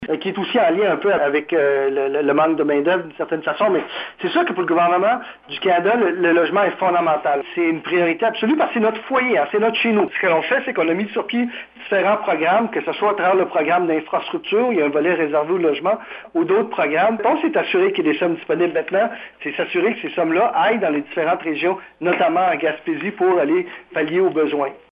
C’est ce qu’a indiqué le lieutenant politique de Justin Trudeau au Québec, Pablo Rodriguez, lors d’un point de presse téléphonique hier alors qu’il était en compagnie de la ministre et députée de la Gaspésie et des Îles, Diane Lebouthillier.